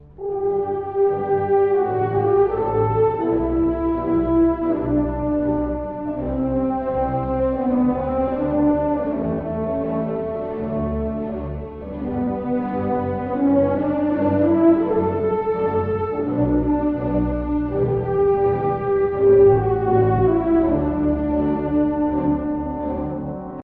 荘厳で、誇りをもったエルガーの情念が目に浮かぶようですね。
なお、原曲に歌はありません。
これらの主部とトリオが2回繰り返されると、コーダに移り、華々しく終わります。